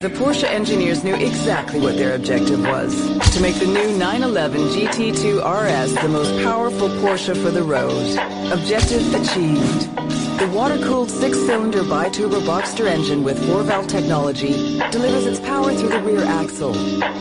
porsche engineering Meme Sound Effect